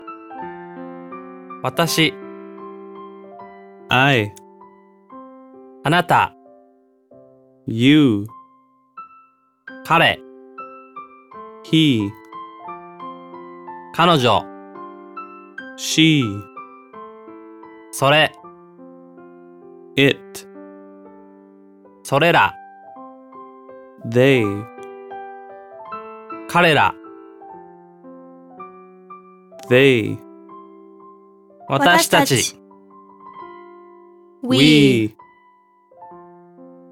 小学生の英語テキスト音源は「日本語→ポーズ1→英語→ポーズ2」と録音してあります。
生徒たちが何度聞いても飽きないようにBGMが入れてあります。
※MP3音源は「日本語→ネイティブ英語」の順に流れます、BGMを聞きながら